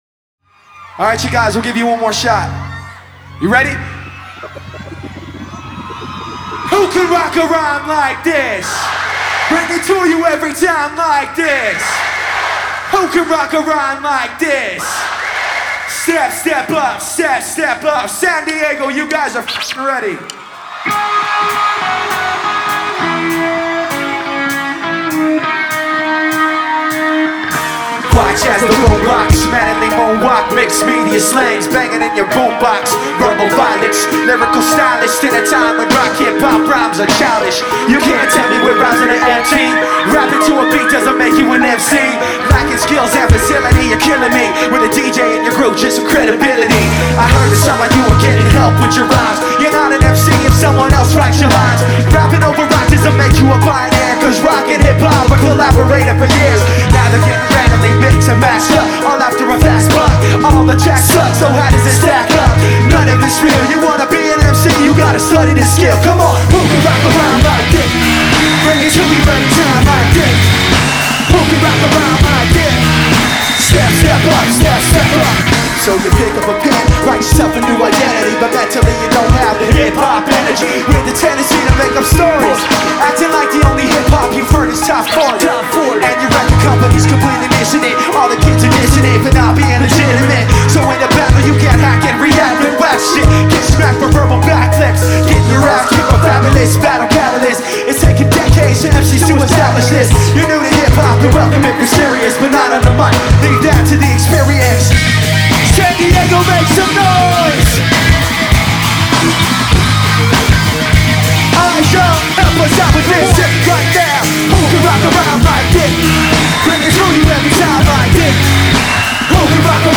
Live Projekt Revolution 2002